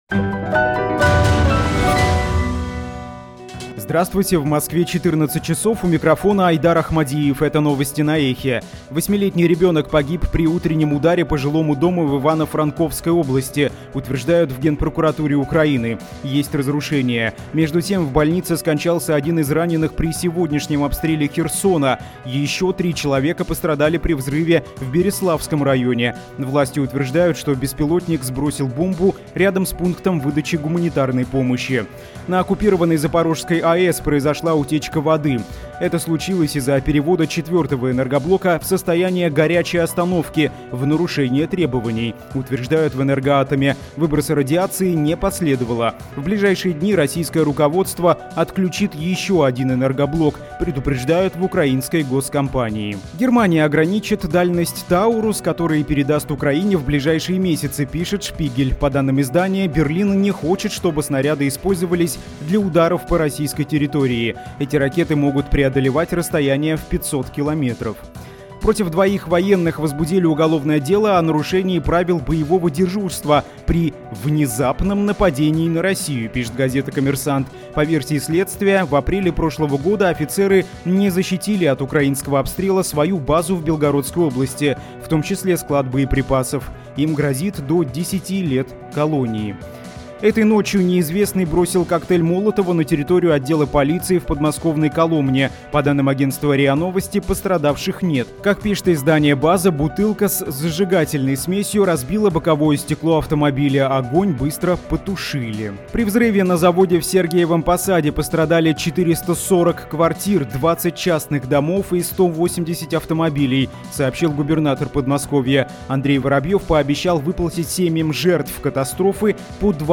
Новости 14:00